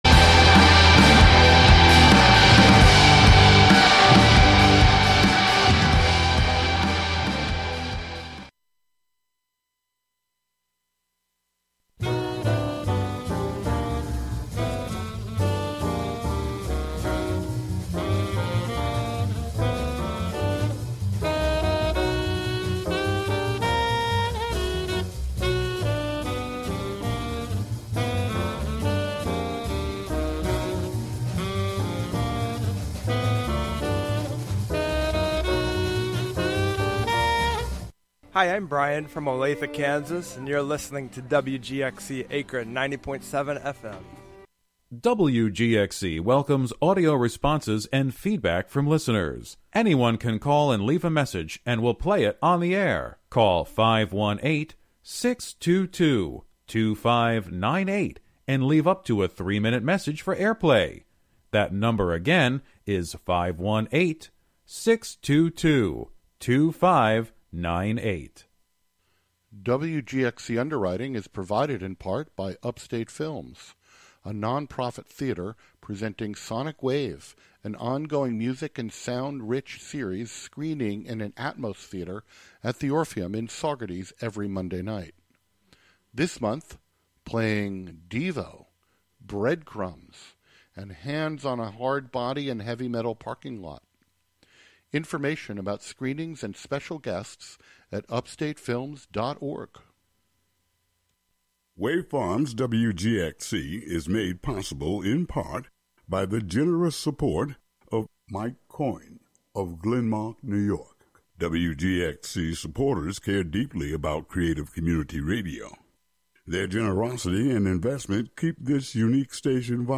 African Dance Music: Jul 21, 2025: 7pm - 8pm